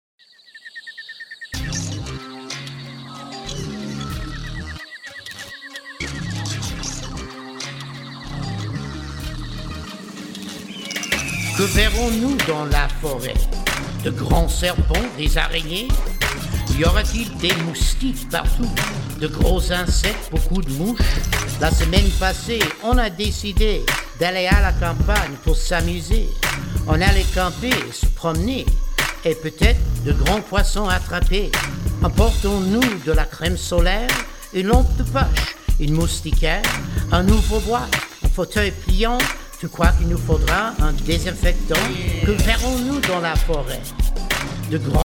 French Language Raps